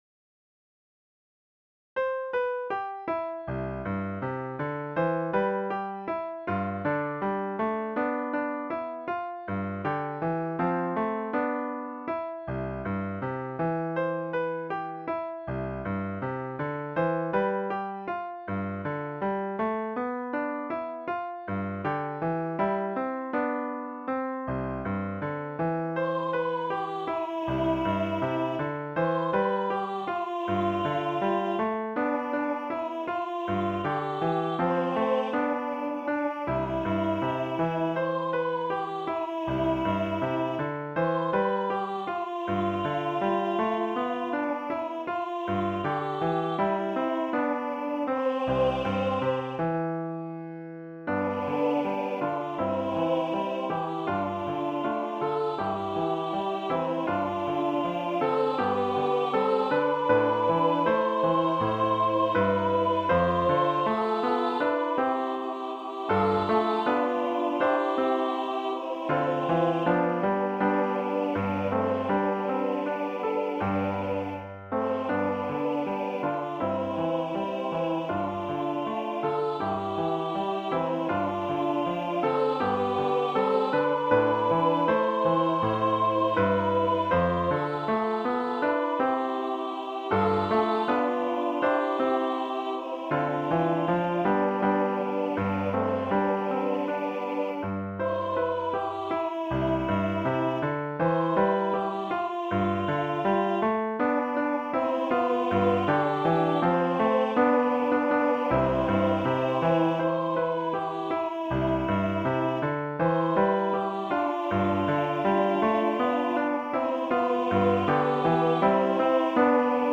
This song was written for the 2014 LDS Young Womens theme. The vocal parts are fairly easy and can be sung by any age. The piano part is easy enough for an early intermediate player. Included is an optional violin obligto for one, or two violins.
Voicing/Instrumentation: SA , Duet